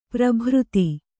♪ prabhřti